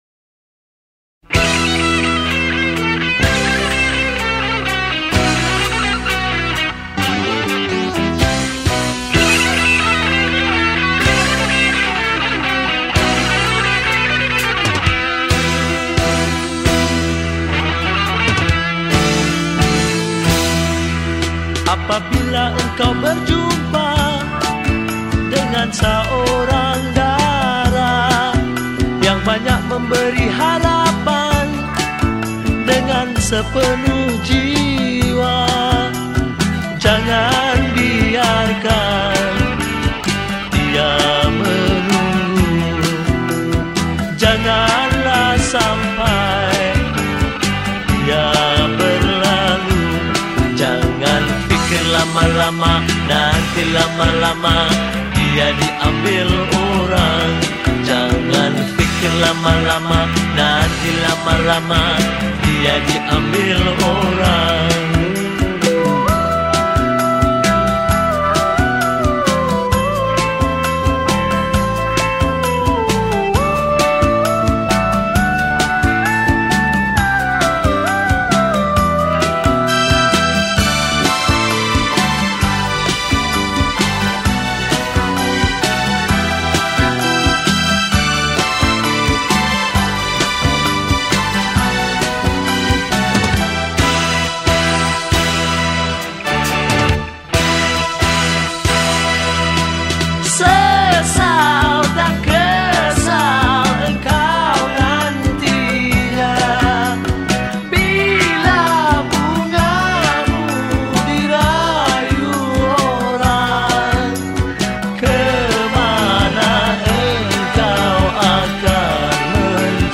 Rock Kapak
Skor Angklung